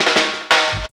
21DR.BREAK.wav